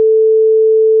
sine440.wav